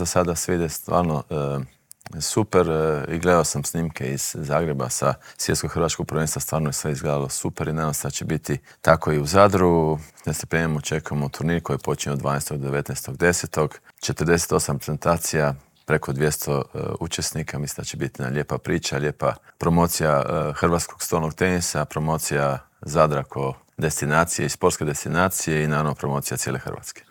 U dvorani Krešimira Ćosića na Višnjiku očekuje nas ekipno prvenstvo Europe, a u Intervjuu tjedna Media servisa ugostili smo predsjednika Hrvatskog stolnoteniskog saveza Zorana Primorca